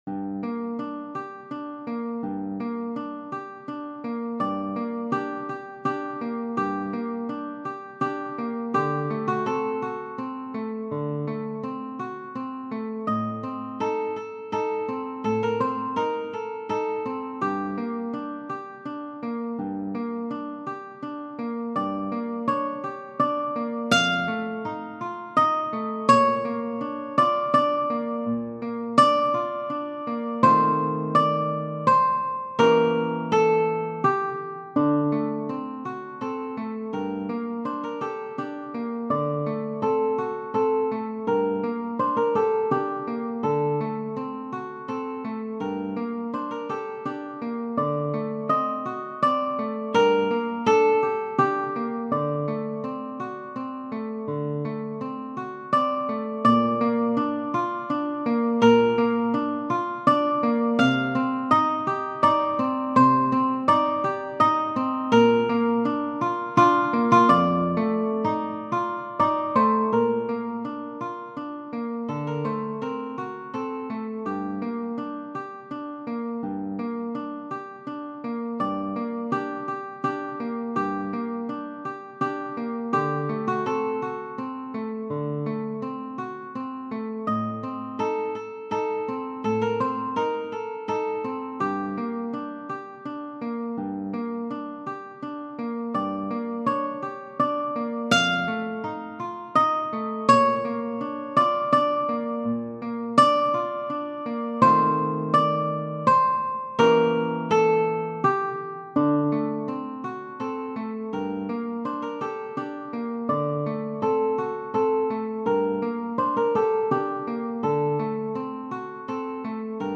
Donizetti, G. Genere: Opera Libretto di Felice Romani Una furtiva lagrima negli occhi suoi spuntò: Quelle festose giovani invidiar sembrò.